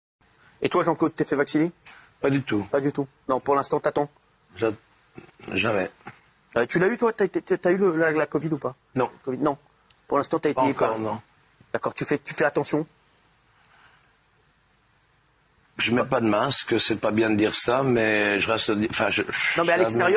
Der belgische Action-Superstar Van Damme erklärt in einer Fernsehsendung, dass er nicht geimpft ist und sich in Zukunft auch nicht impfen lässt.